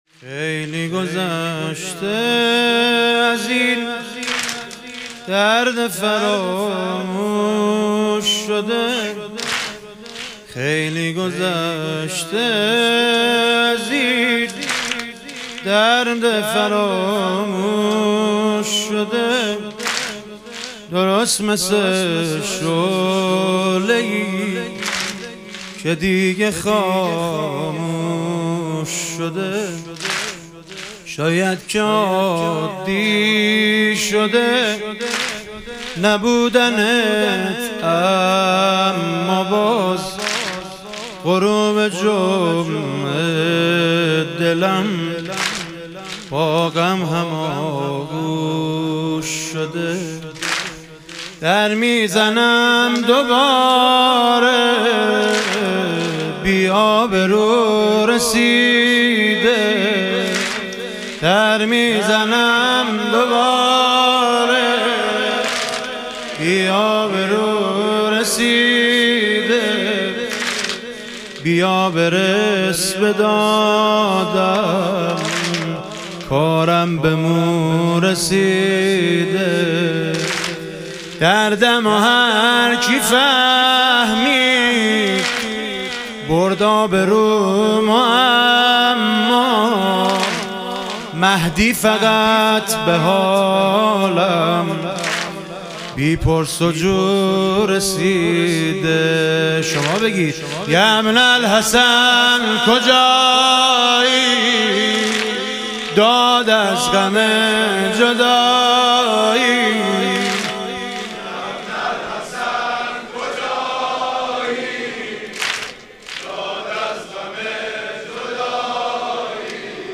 شهادت حضرت زینب کبری علیها سلام - واحد